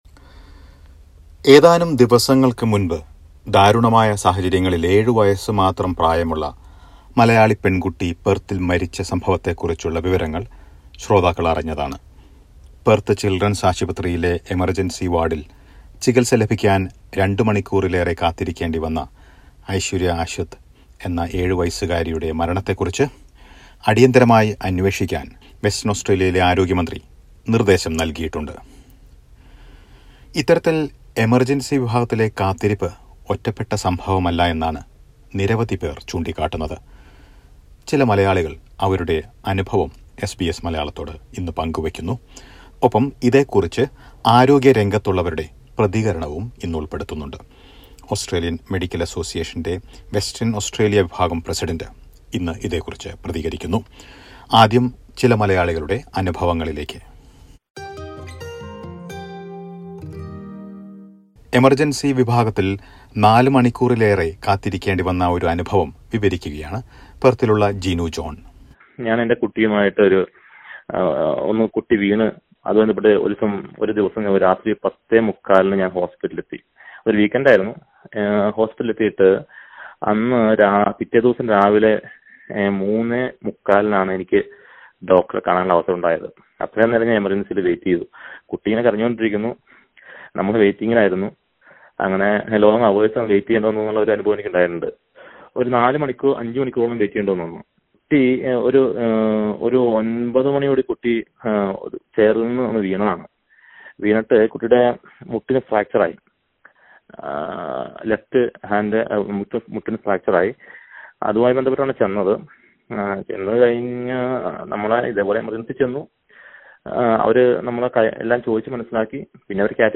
mlm_804_voxpop_and_ama_reaction.mp3